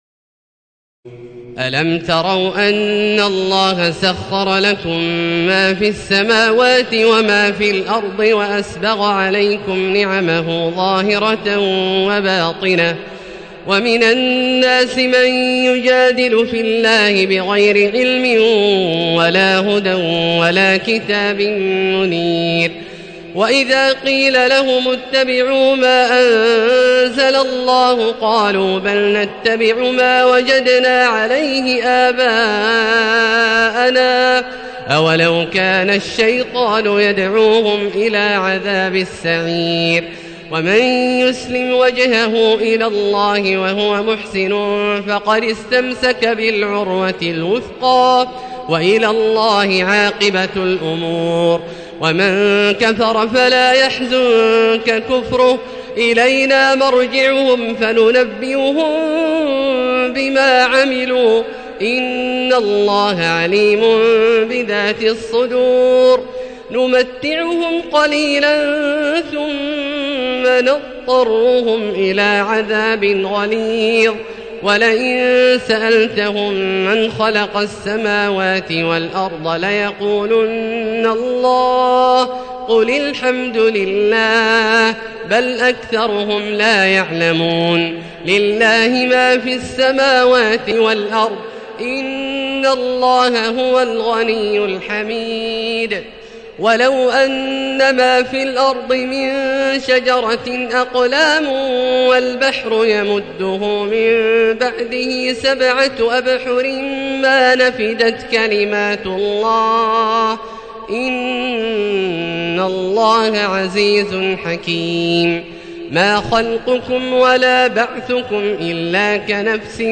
تراويح الليلة العشرون رمضان 1437هـ من سور لقمان (20-34) والسجدة و الأحزاب (1-31) Taraweeh 20 st night Ramadan 1437H from Surah Luqman and As-Sajda and Al-Ahzaab > تراويح الحرم المكي عام 1437 🕋 > التراويح - تلاوات الحرمين